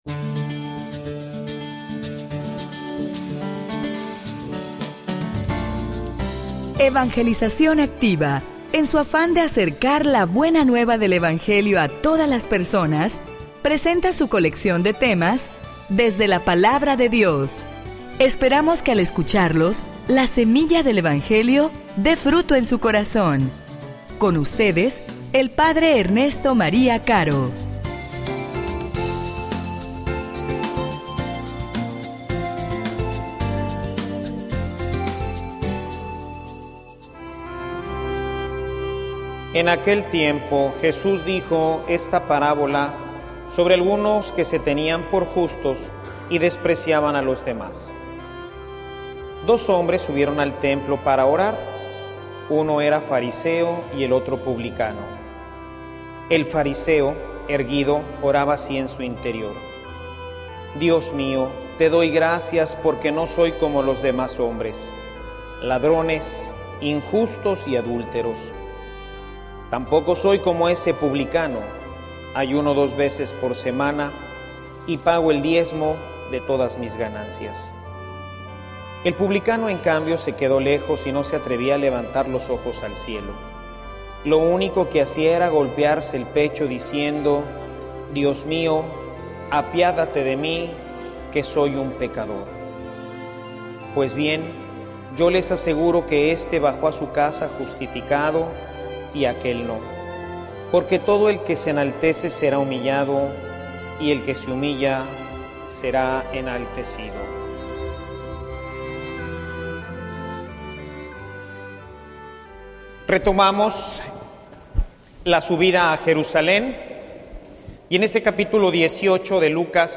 homilia_Tu_tambien_necesitas_a_Dios.mp3